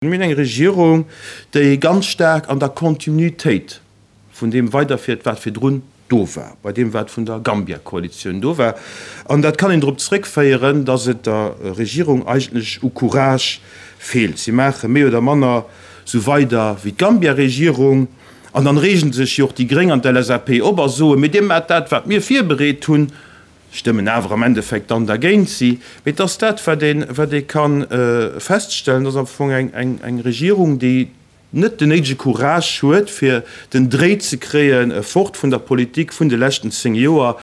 Presseiessen
Dee Bilan zitt d’ADR um traditionelle Presseiessen um Enn vum Chamberjoer.